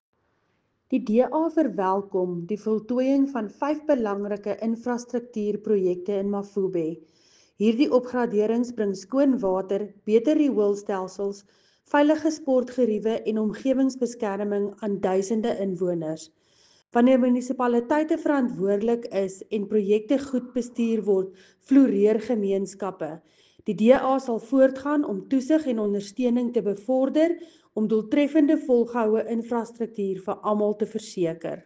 Afrikaans soundbite by Cllr Linda Louwrens and